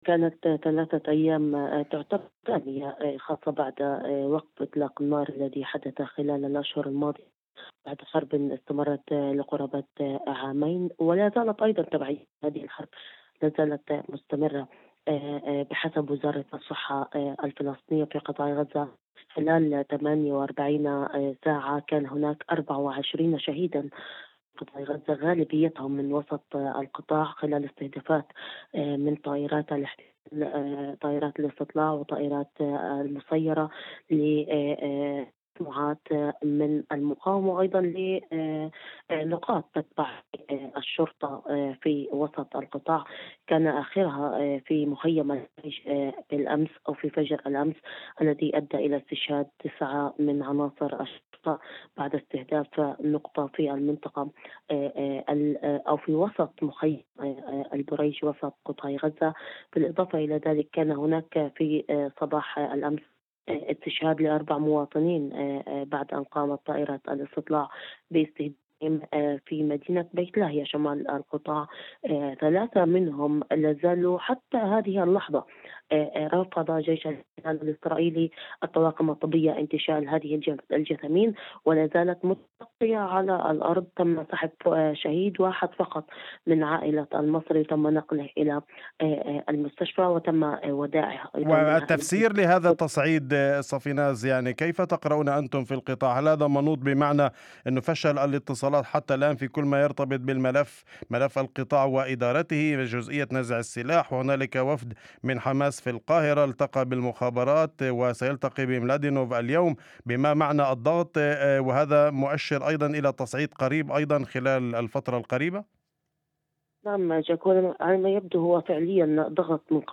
وأضافت في مداخلة هاتفية ضمن برنامج "أول خبر" عبر إذاعة الشمس، أن من أبرز هذه الهجمات استهداف نقطة للشرطة في مخيم البريج، ما أدى إلى ارتقاء تسعة من عناصر الشرطة، إضافة إلى استهداف في بيت لاهيا شمال القطاع أدى إلى ارتقاء أربعة مواطنين.